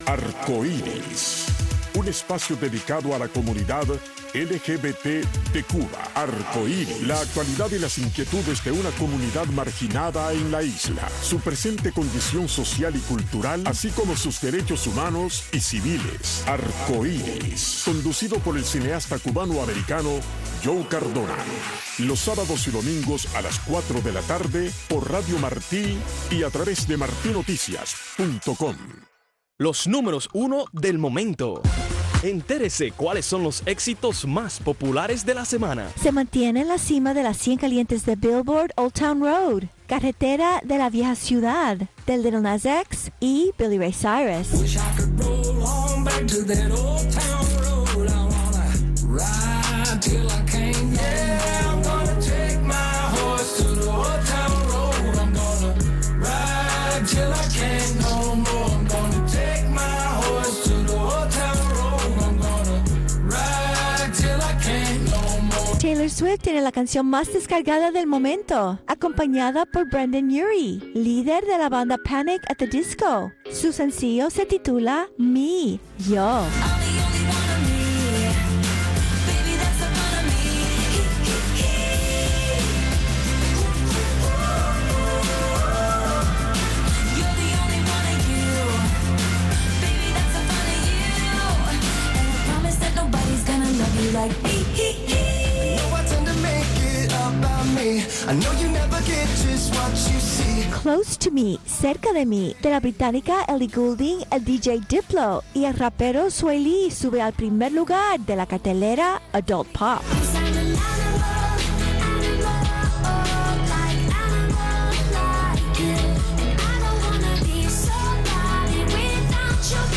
Soy Guajiro es un programa para los campesinos y guajiros con entrevistas, música y mucho más.